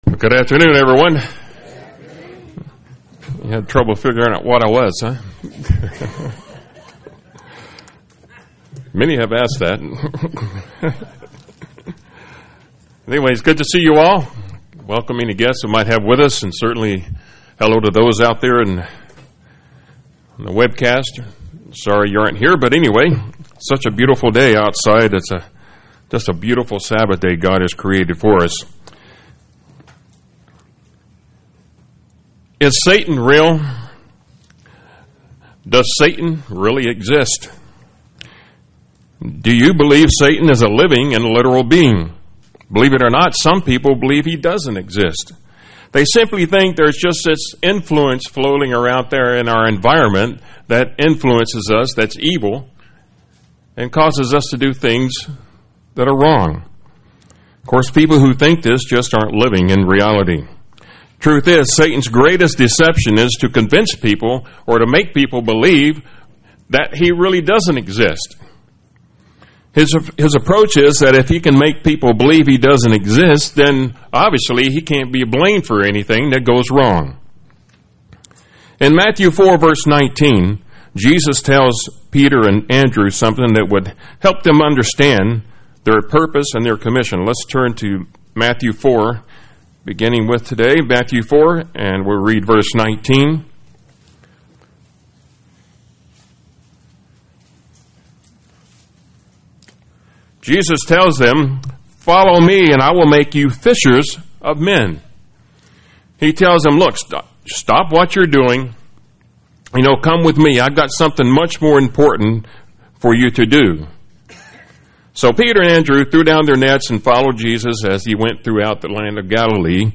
In this sermon we'll see and examine Satan's Modus Operandi as he cast his net of deception as he accuses the people of God. We'll see how we can avoid getting snared in this net or getting hooked by his tricks or bait that he dangles before us.
Given in Tampa, FL